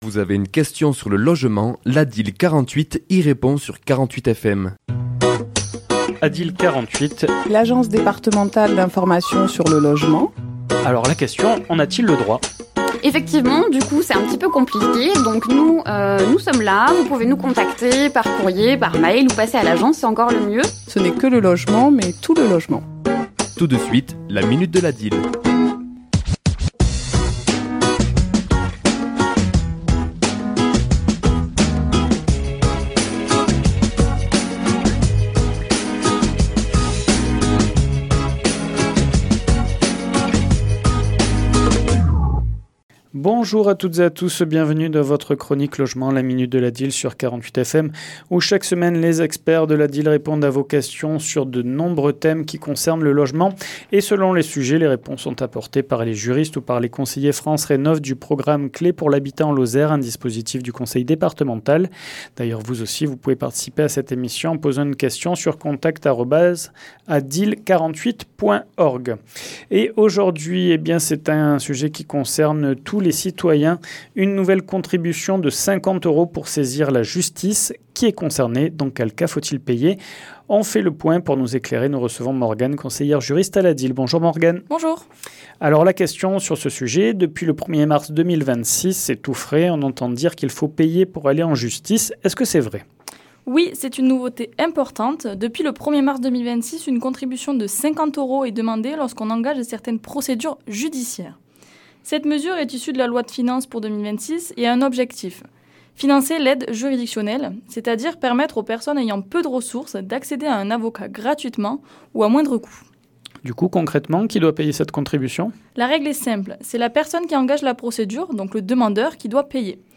Chronique diffusée le mardi 8 avril à 11h et 17h10